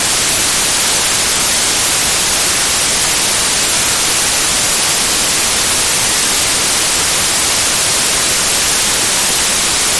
На этой странице собраны разнообразные звуки белого шума, включая классическое шипение, помехи от телевизора и монотонные фоновые частоты.
Белый шум успокаивающий